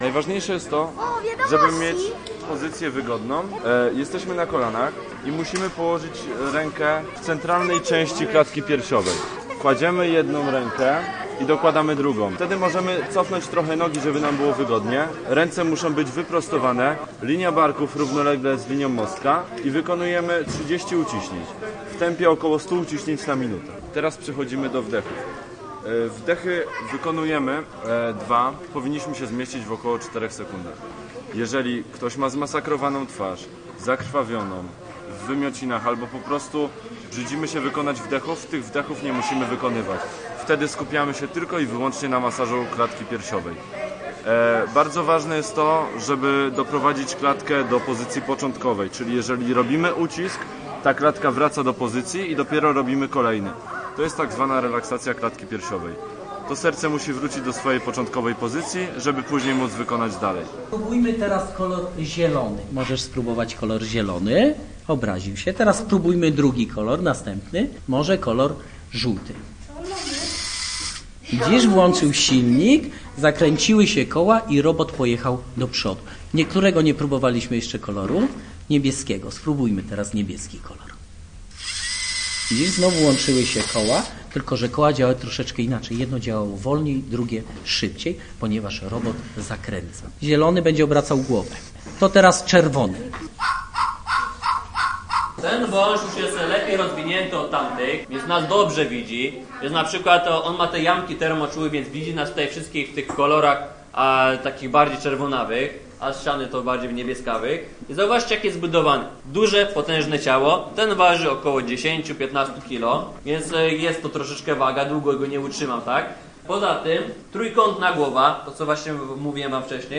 Z naszym mikrofonem odwiedziliśmy pokaz udzielenia pierwszej pomocy medycznej, warsztaty z robotami i na koniec sprawdziliśmy, co słychać u pytona tygrysiego.